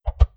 Close Combat Swing Sound 48.wav